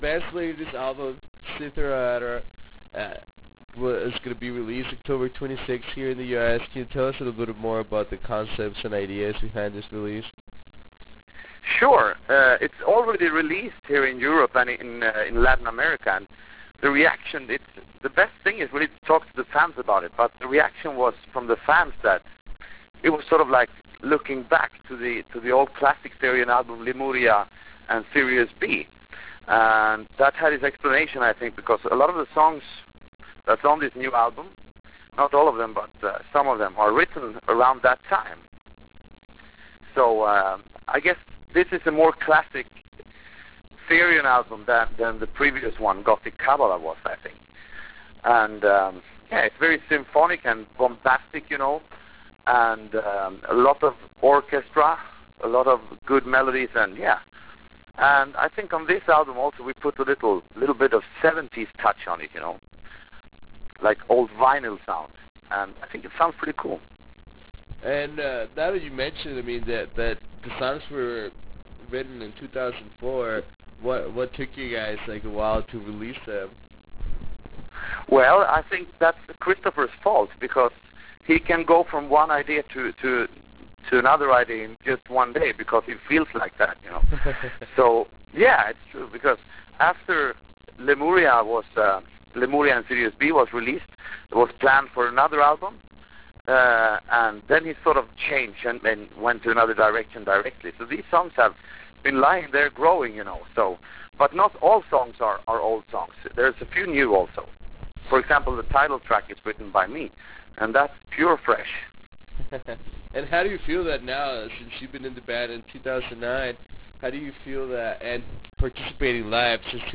Interview with Therion - Thomas Vikstrom